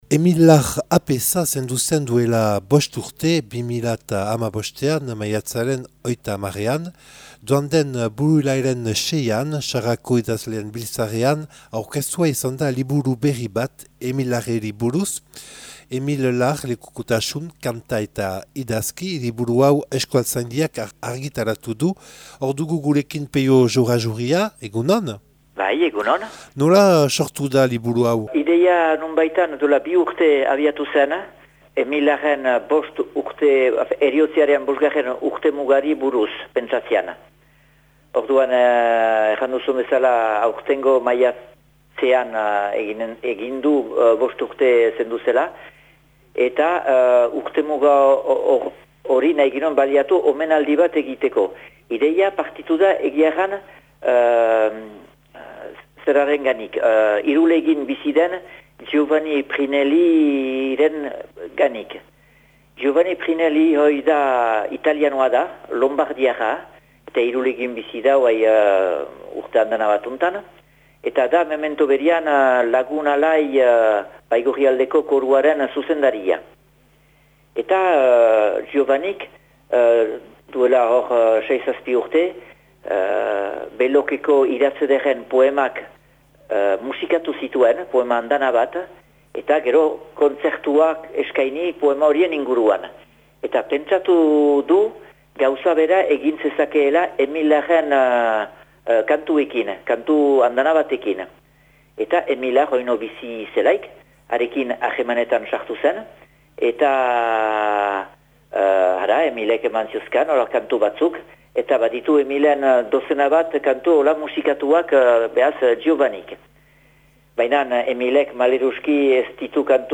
Elkarrizketak eta erreportaiak